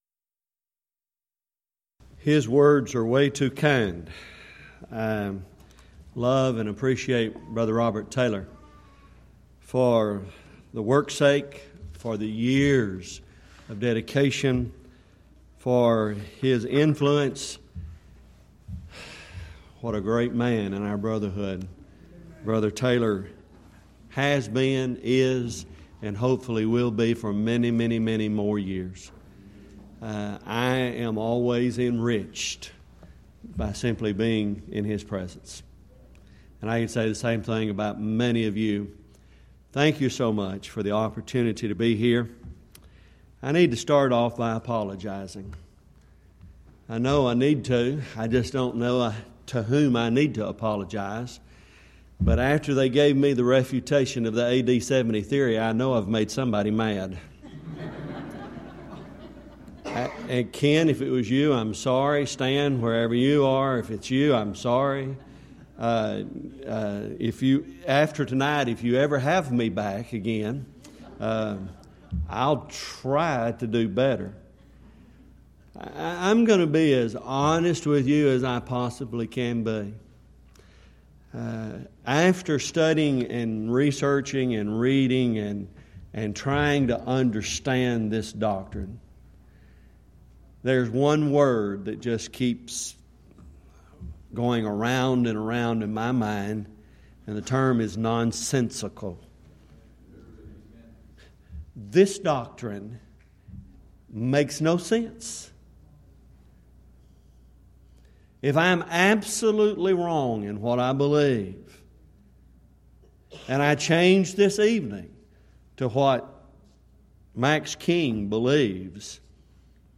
Event: 11th Annual Schertz Lectures
If you would like to order audio or video copies of this lecture, please contact our office and reference asset: 2013Schertz15